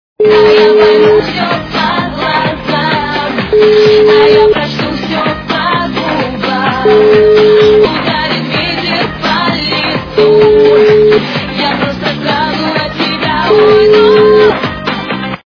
ремикс качество понижено и присутствуют гудки.